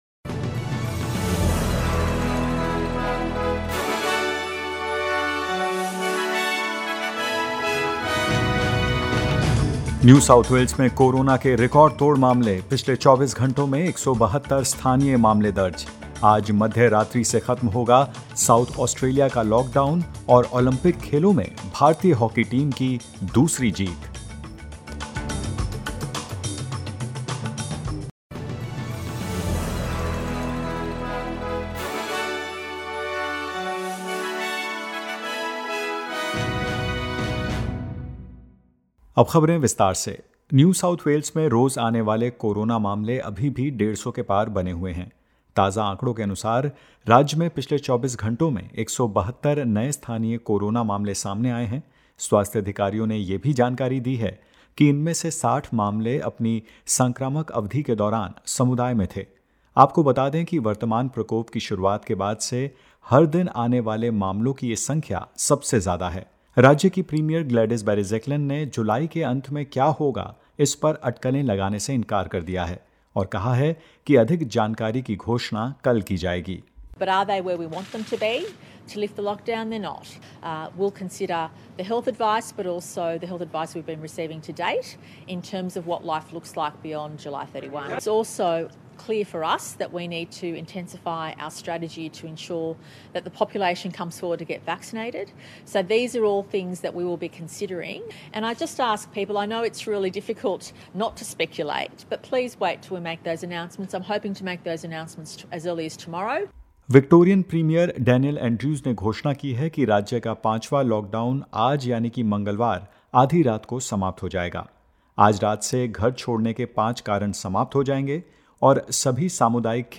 In this latest SBS Hindi News bulletin of Australia and India: Victoria and SA end lockdown at midnight; No new cases in SA and Queensland and more.